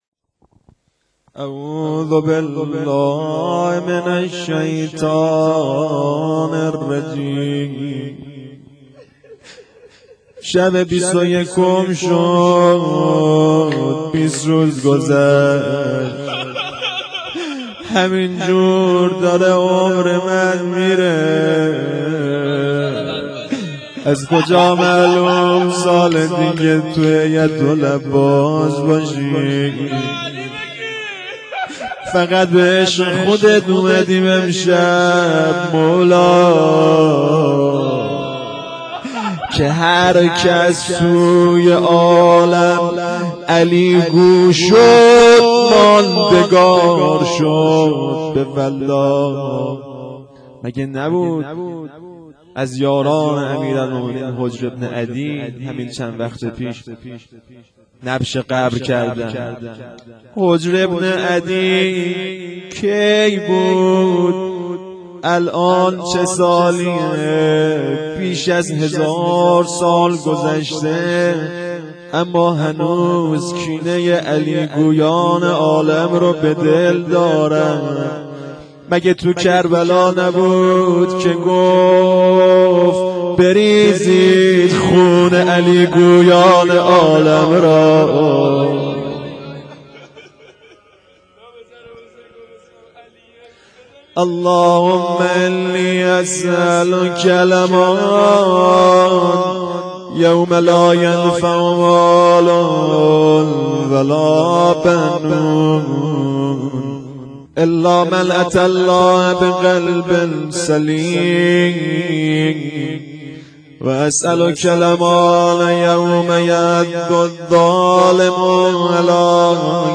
شب 21 رمضان 92